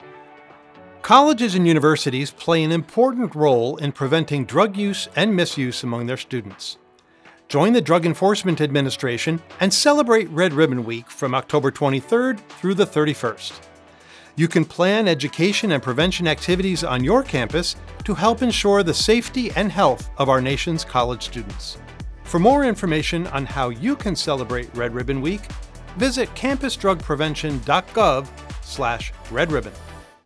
Red Ribbon Week Radio PSA
The Drug Enforcement Administration (DEA) has produced a radio PSA (30 seconds) related to Red Ribbon Week to encourage prevention professionals on college and university campuses to participate in Red Ribbon.